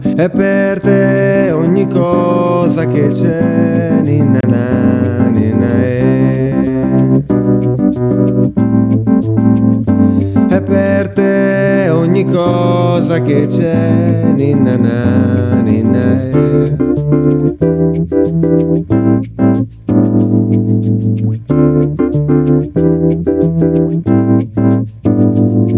припев: